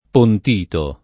[ pont & to ]